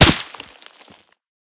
bulletFlyBy_2.ogg